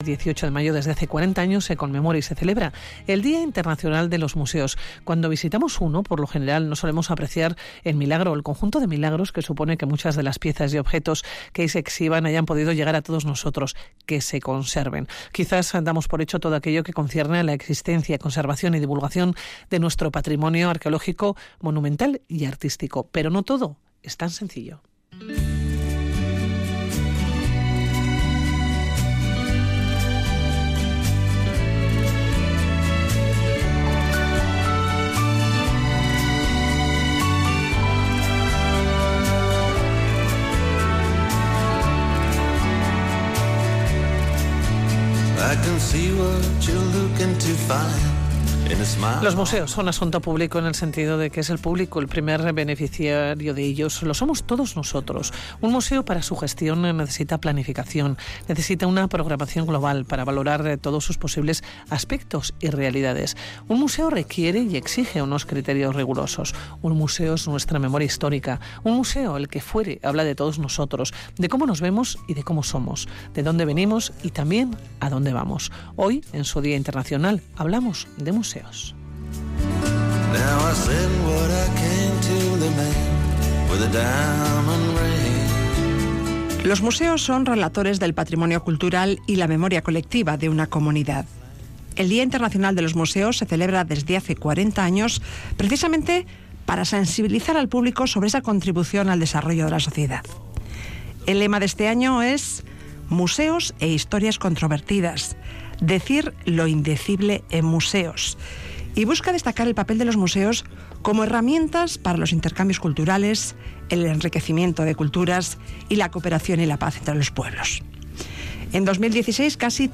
Radio Vitoria| Hoy día de los museos realizamos una mesa redonda con responsables de algunos de los museos de álava. Hablamos su presente y futuro.